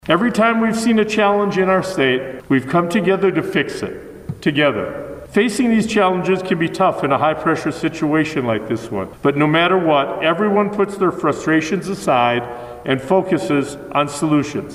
Governor Steve Sisolak announced two major updates to the state’s vaccination timeline at his news conference Wednesday.